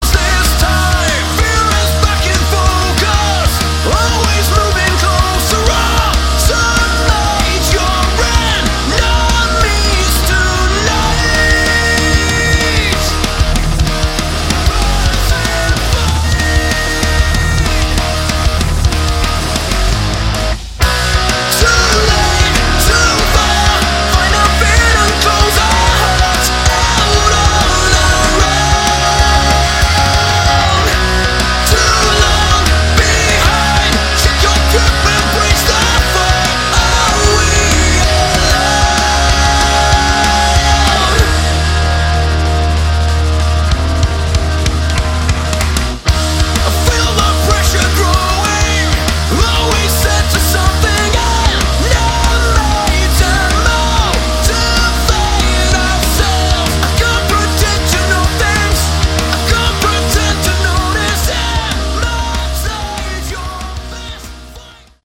Category: Modern Hard Rock
vocals, bass
guitar, vocals
drums